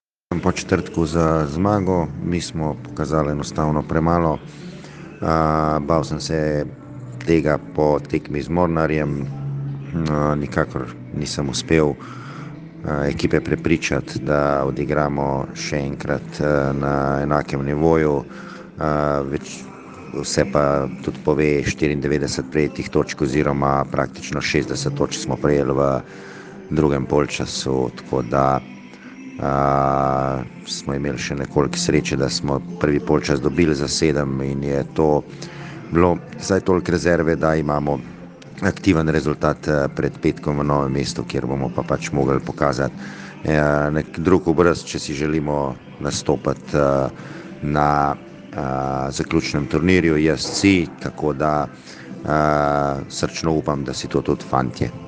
Izjava po tekmi: